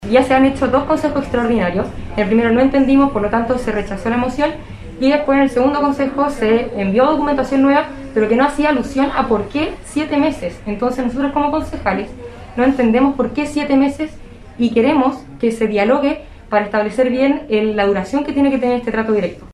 Y es que con votos de rechazo y abstenciones los ediles se negaron a autorizar el contrato directo de una empresa para prestar el servicio, según explicó la concejala de la UDI, Valentina Pávez.